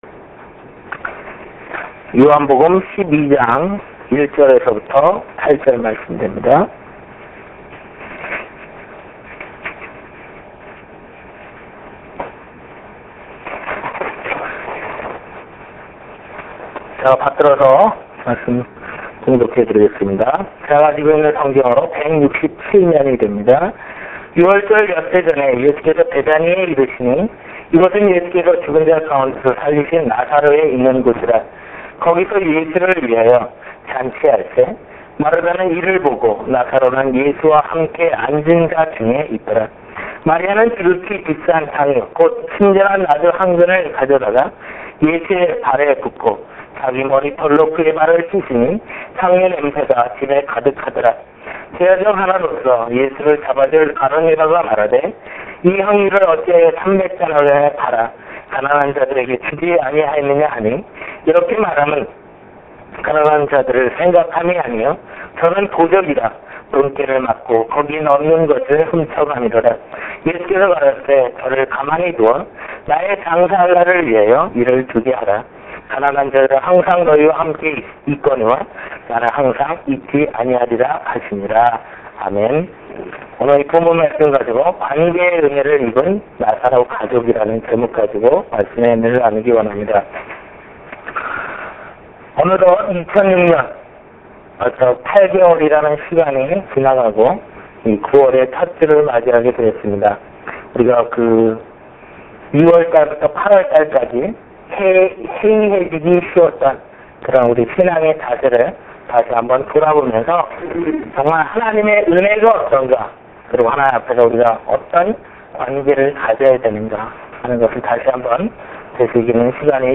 빅토빌예수마음교회 주일설교파일 - 주일설교 9월 3일